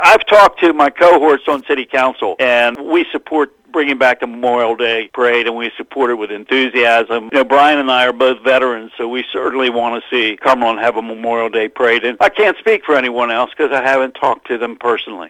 City Council member Rock Cioni commented that there is support for the event…